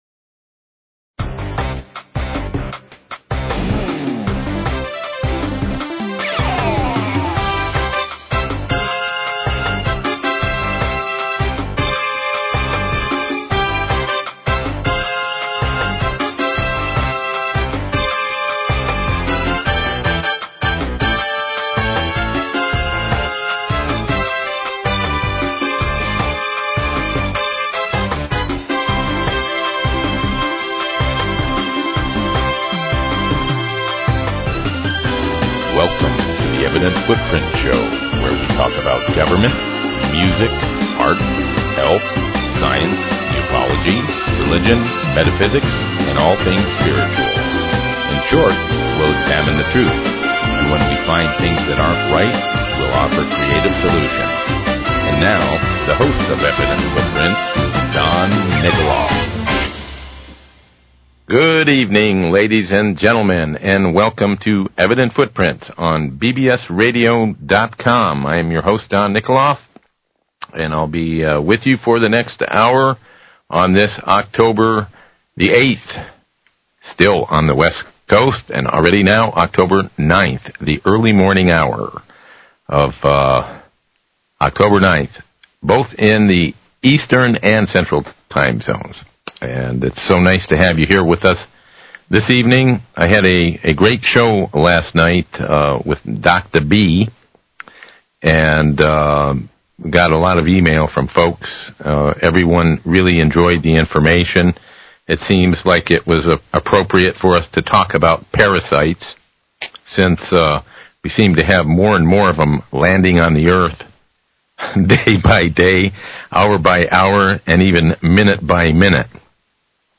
Talk Show Episode, Audio Podcast, Evident_Footprints and Courtesy of BBS Radio on , show guests , about , categorized as
Civil Rights/Antitrust Attorney